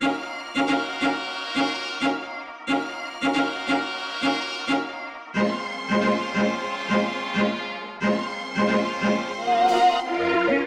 dhh2_royal_brass_loop_90_A#m
dhh2_royal_brass_loop_90_Am.wav